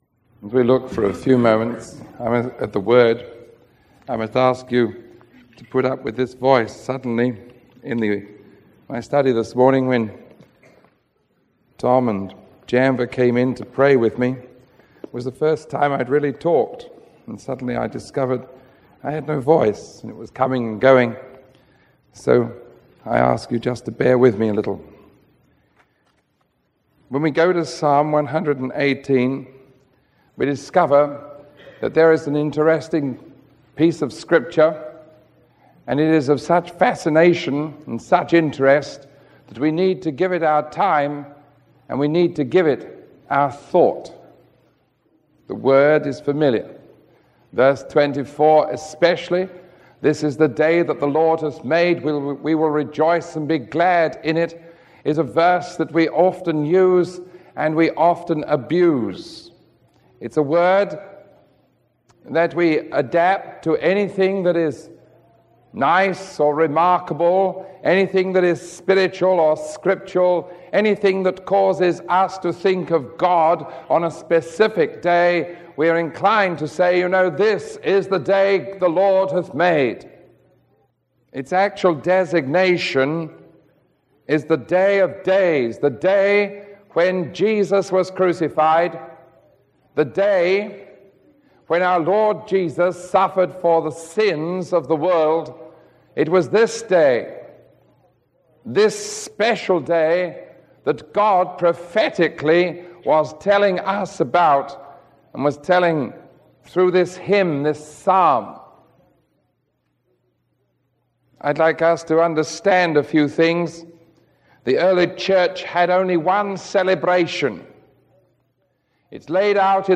Communion